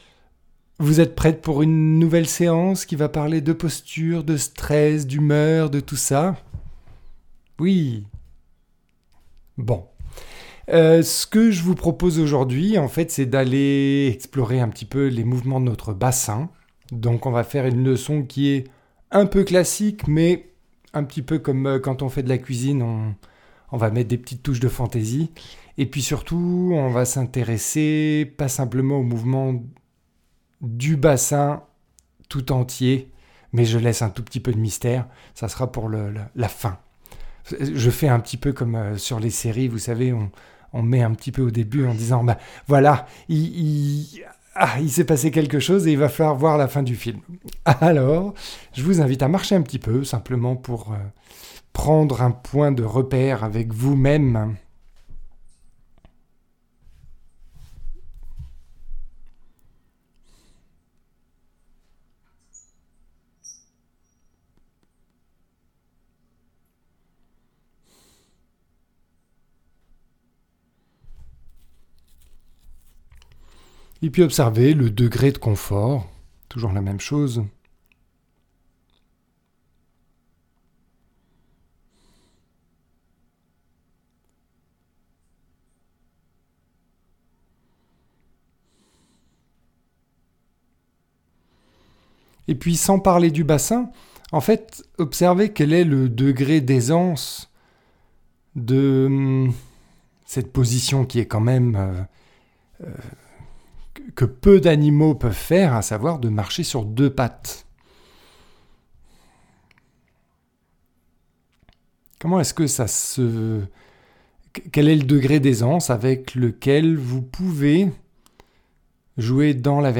Une leçon pour découvrir les mobilités du bassin (externes et internes) – Feldenkrais Replay